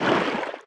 Sfx_creature_brinewing_swim_attack_exit_01.ogg